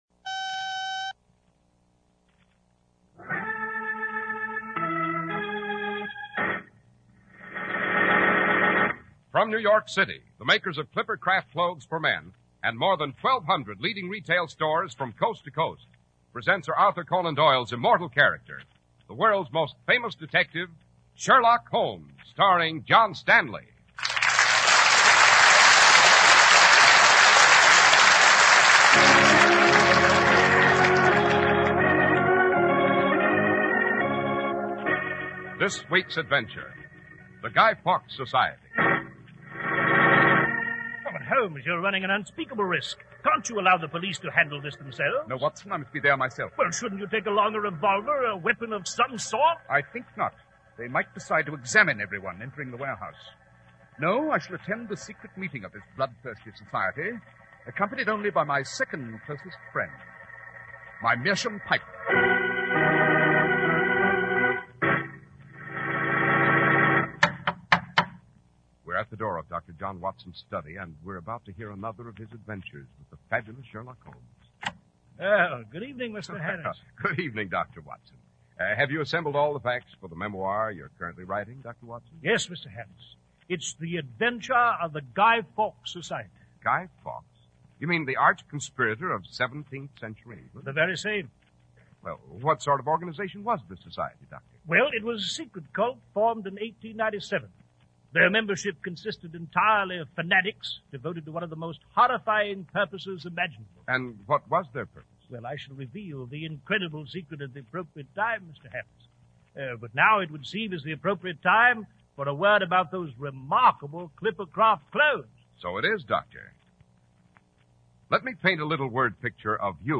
Radio Show Drama with Sherlock Holmes - The Guy Fawkes Society 1948